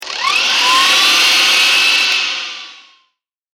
機械・乗り物 （94件）
近未来動作音4.mp3